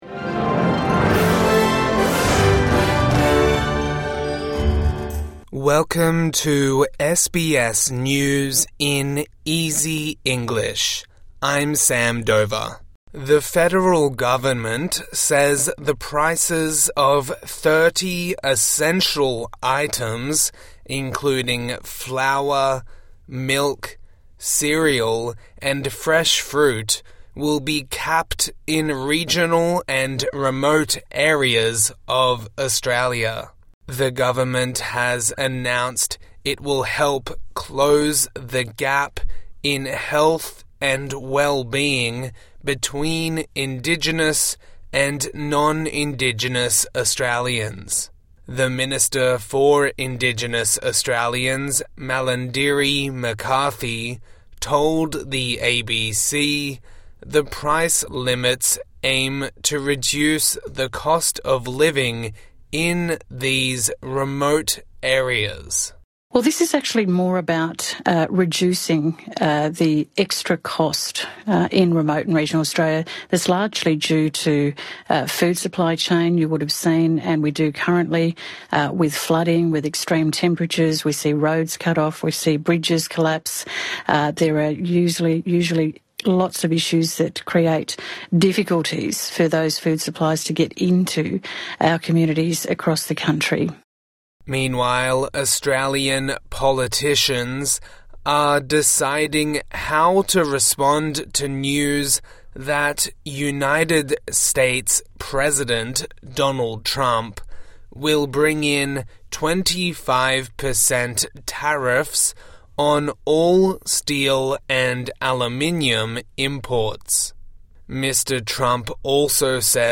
A daily five minute news wrap for English learners and people with disability.